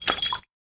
ITEM_liquid.ogg